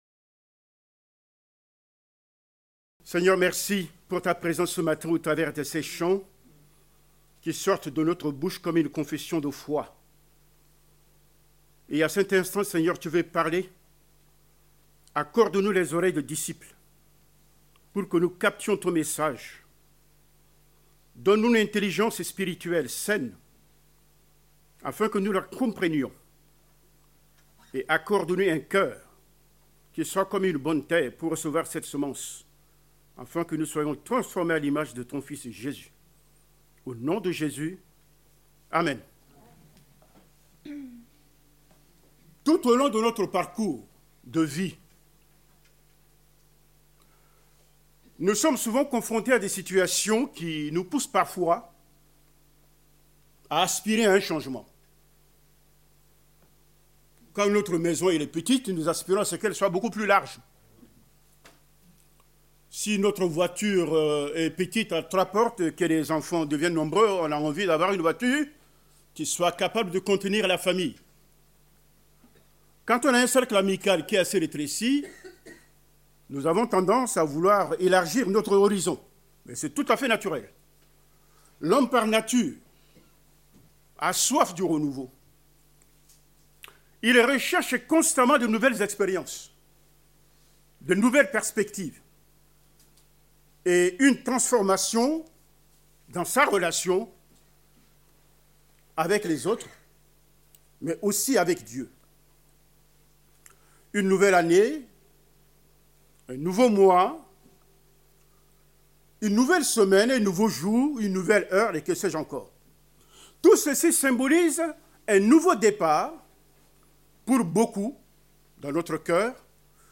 Prédication du 30 novembre 2025.